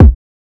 Kick (BallDrop).wav